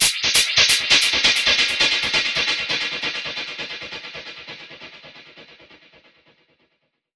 Index of /musicradar/dub-percussion-samples/134bpm
DPFX_PercHit_E_134-01.wav